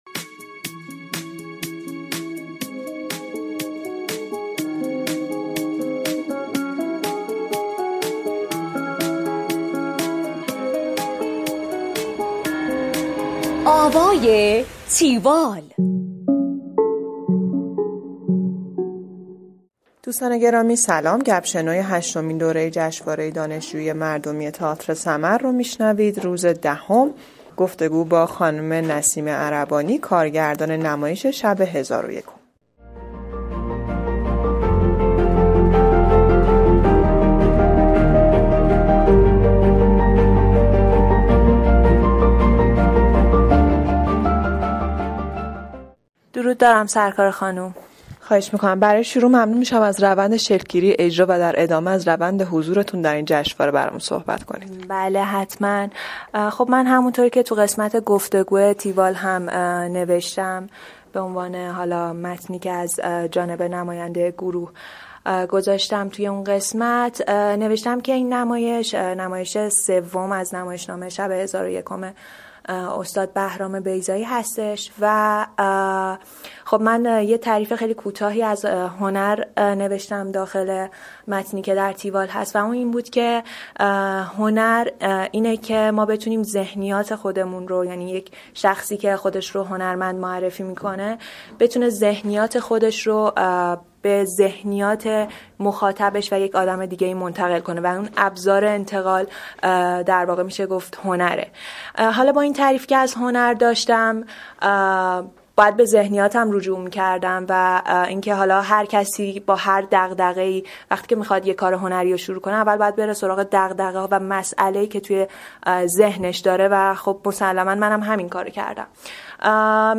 گفتگوی تیوال